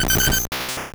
Cri de Taupiqueur dans Pokémon Or et Argent.